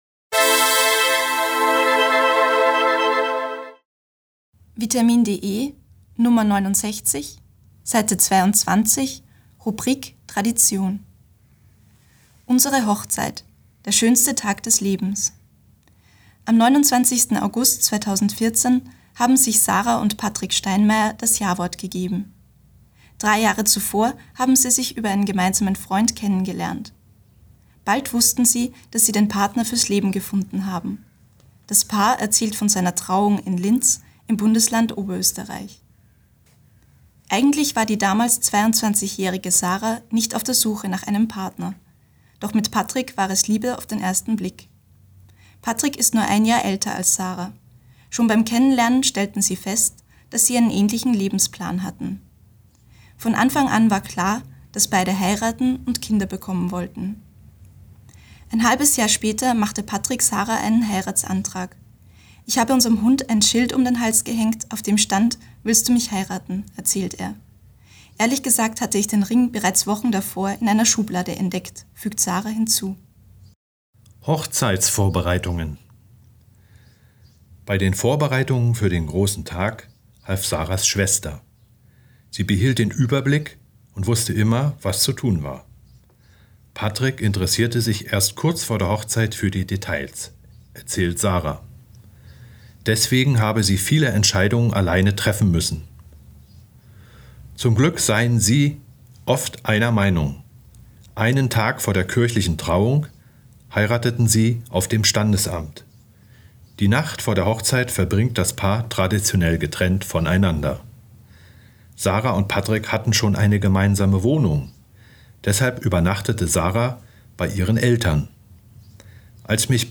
Audiodatei (Hörversion) zum Text